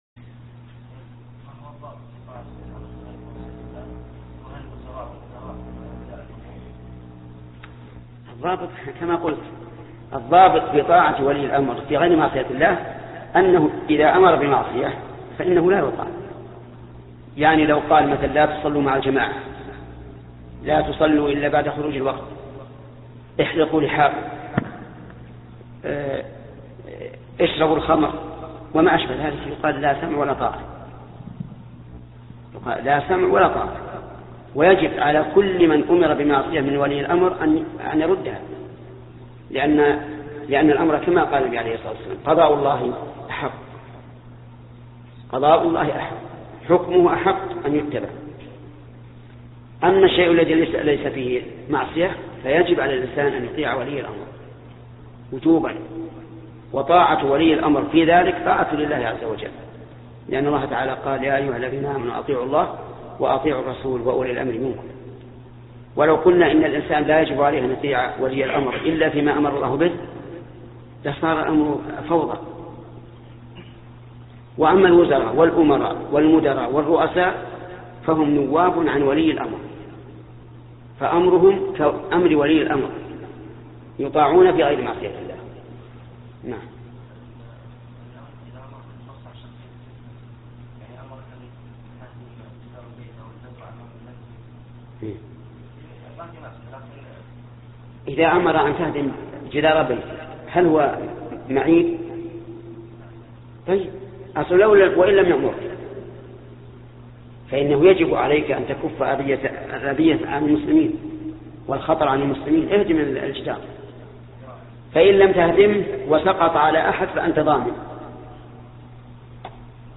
موقع النهج الواضح يسهر على نقل الدروس العلمية عبر إذاعته، وكذلك نشر دروس وشروحات ومحاضرات ومقالات كبار العلماء، كما ينظم لقاءات مفتوحة مع العلماء للإجابة على أسئلة المستمعين والزوار ومن ثم نشرها في قسم الفتاوى بالموقع.